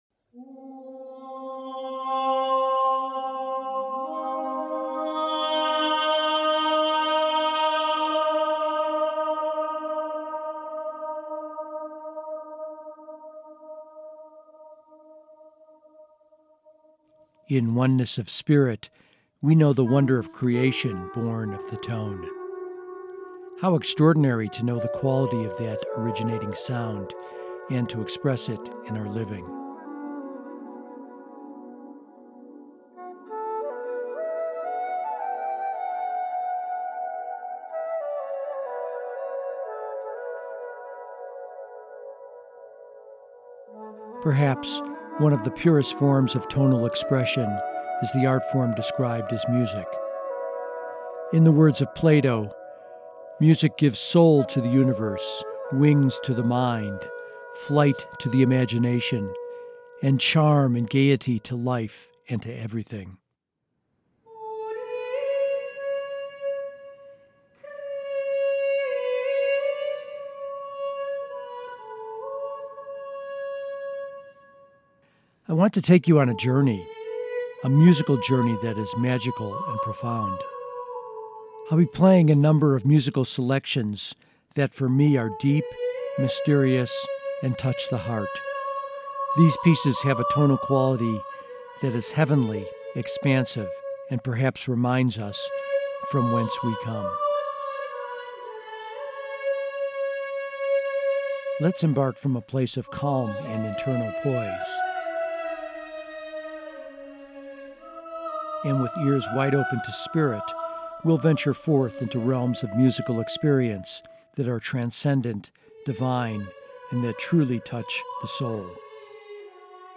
I created it as a podcast, an audio essay comprised of music interspersed with some spoken words. I invite you to listen and touch the power and magic of spirit as it emerges in musical form.